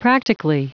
Prononciation du mot practically en anglais (fichier audio)
Prononciation du mot : practically